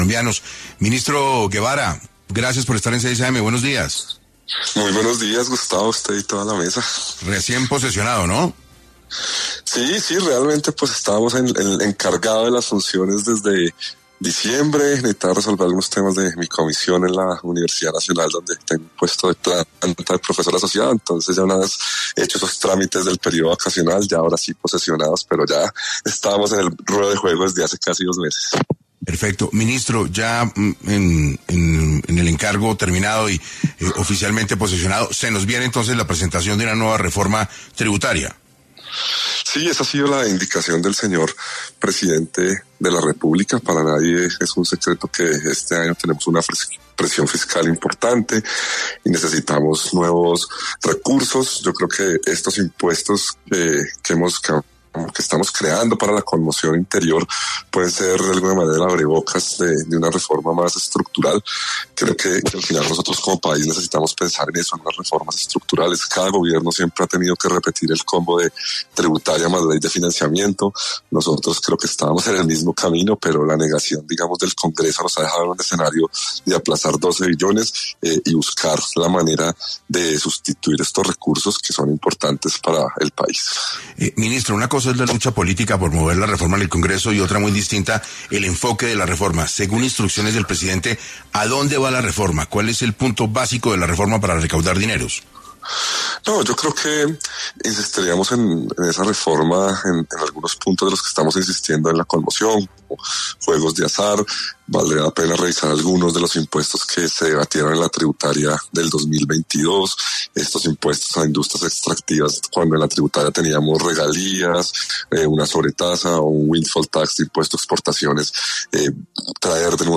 En entrevista con 6AM de Caracol Radio, el ministro Guevera confirmó que la orden del presidente Petro es impulsar una nueva reforma tributaria con un piso de $12 billones y, los nuevos impuestos son un estímulo para elaborarla: